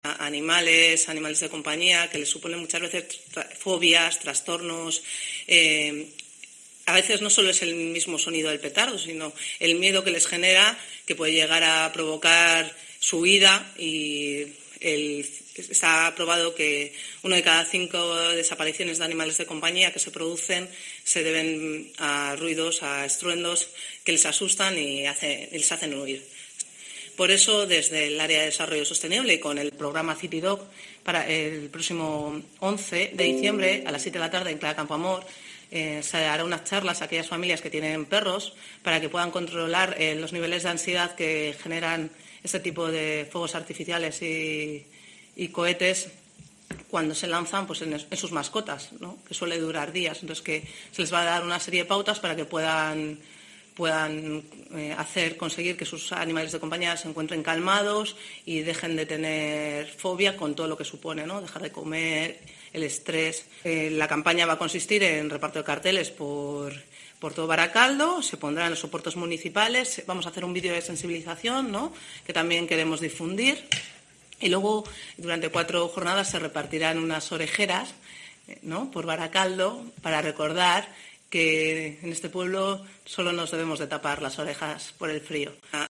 Alba Delgado, concejal de Barakaldo